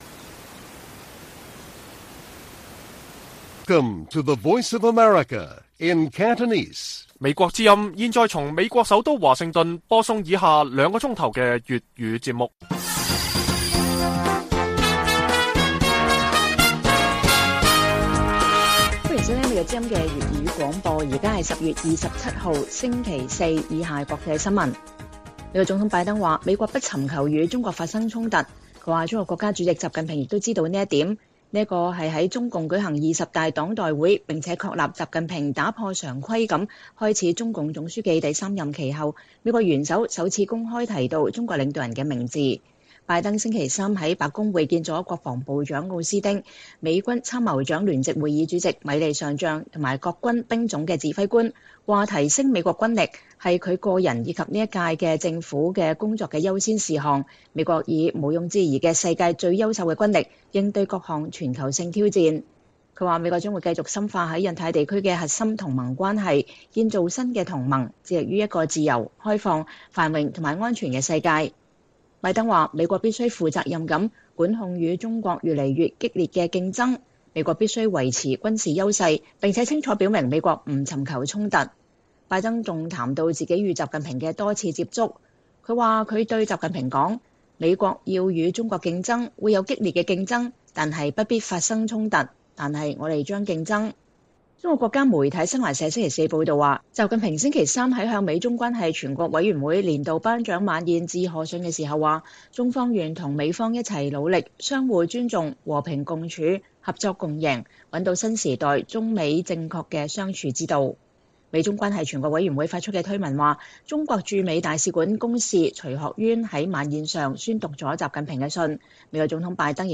粵語新聞 晚上9-10點 : 擬議中的拜習巴厘峰會或破局？